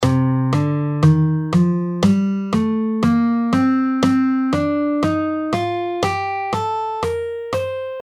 C Dorian
C Dorian: C – D – E♭ – F – G – A – B♭ – C. Similar to the minor scale but with a raised sixth, giving it a more complex, jazzy feel.
C-Dorian-2nd-Mode-Of-C-Major.mp3